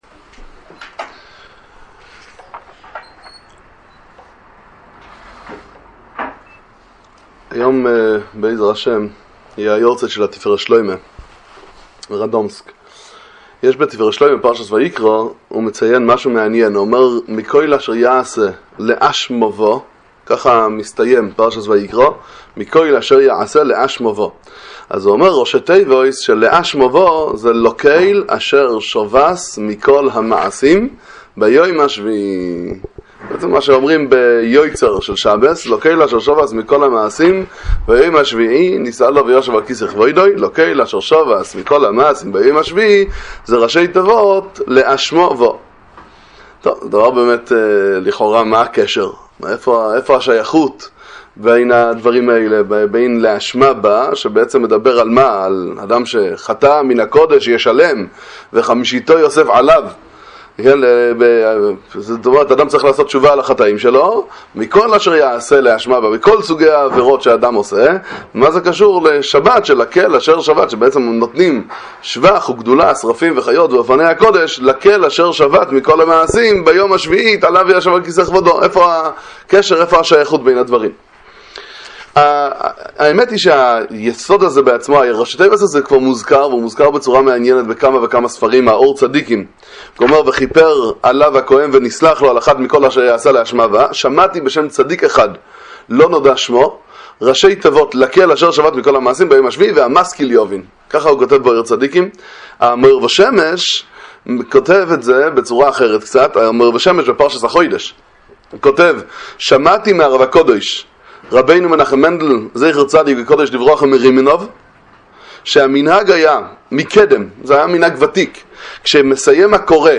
דבר תורה קצר לפרשת ויקרא מספר תפארת שלמה ומאור ושמש, שיעורי תורה על פרשת השבוע